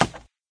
woodplastic3.ogg